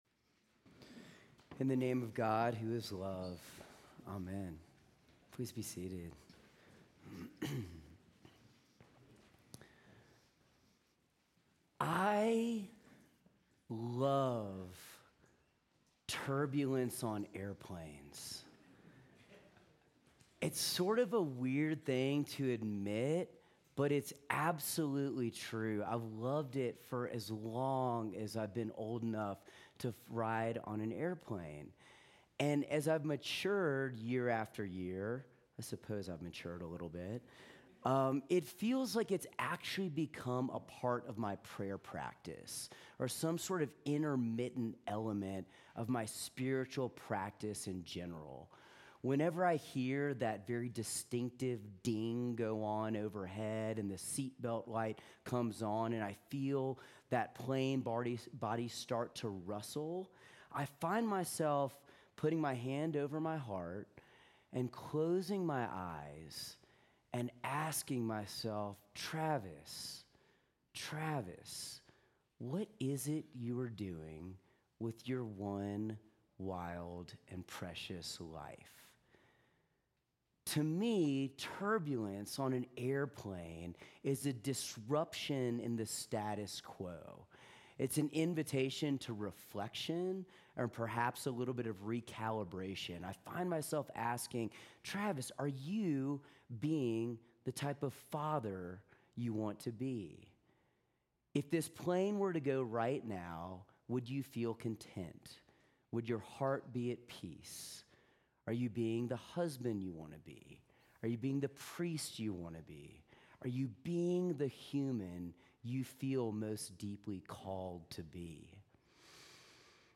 Sermons
St. John's Episcopal Church